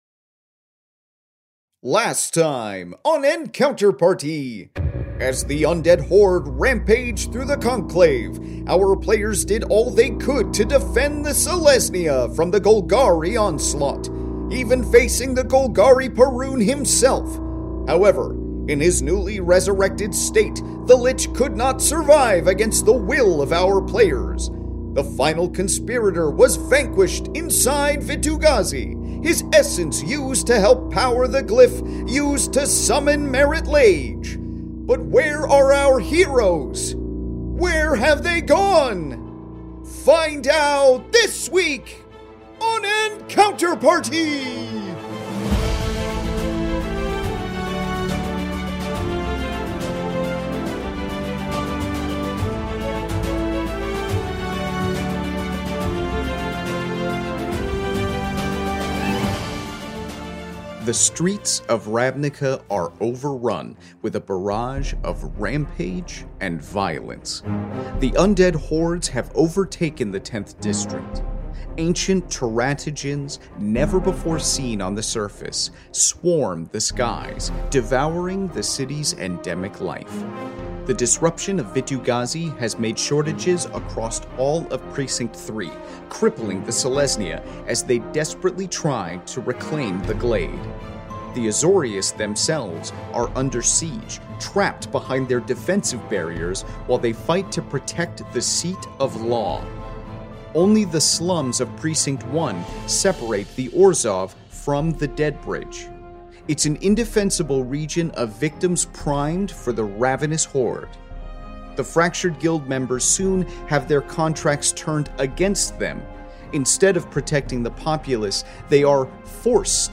Step inside the Magic: the Gathering world of Ravnica in this Fantasy Mystery Audio Adventure governed by the rules of Dungeons & Dragons